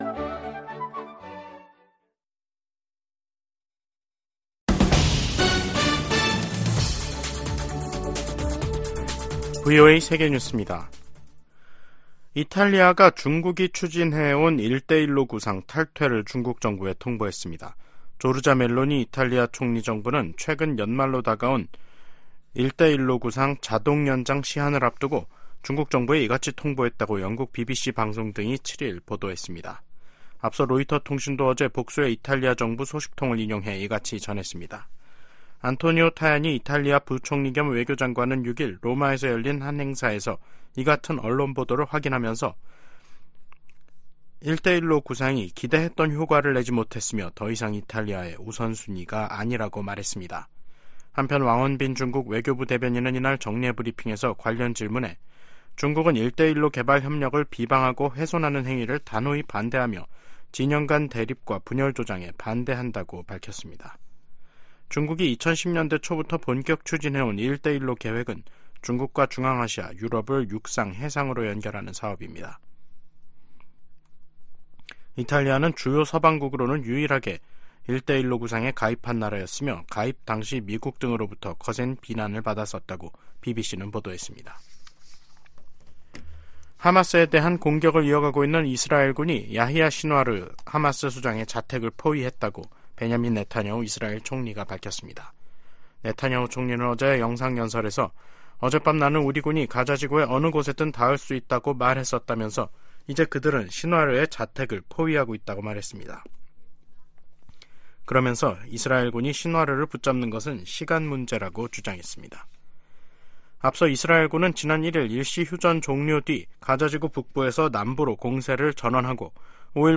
세계 뉴스와 함께 미국의 모든 것을 소개하는 '생방송 여기는 워싱턴입니다', 2023년 12월 7일 저녁 방송입니다. '지구촌 오늘'에서는 안토니우 구테흐스 유엔 사무총장이 가자지구 내 임박한 인도적 재앙을 경고하며 유엔헌장 99조를 발동한 소식 전해드리고, '아메리카 나우'에서는 우크라이나 지원 등이 포함된 1천 110억 달러 규모 지원 예산안이 상원에서 절차 투표 통과에 실패한 이야기 살펴보겠습니다.